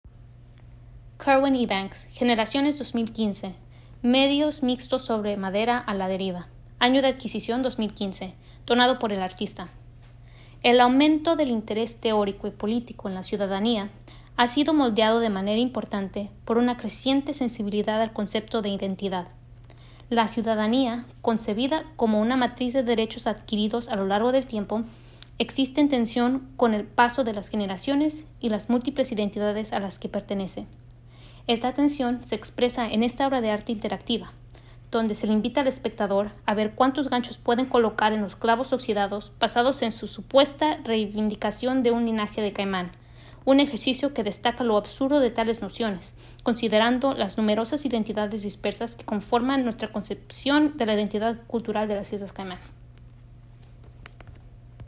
Generaciones Narración